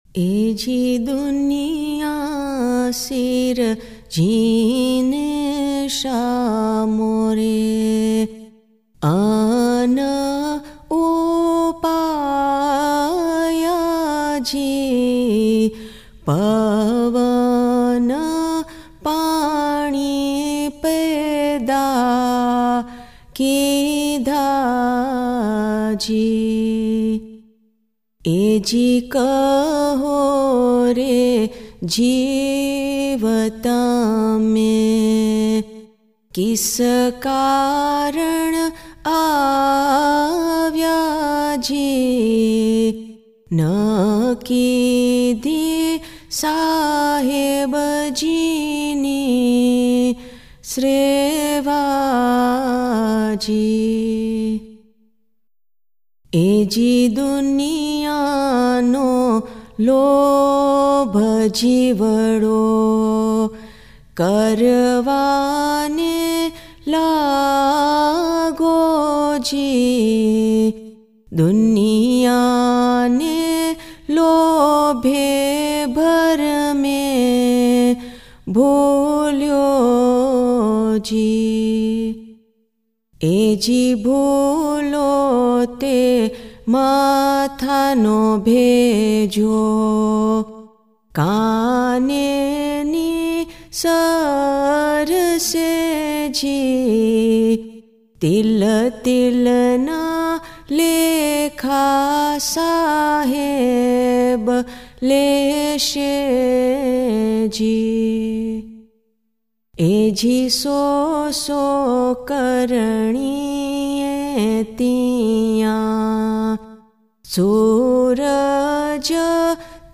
Ginan: Duniya Sirajine Shaah More – Having created the world my Lord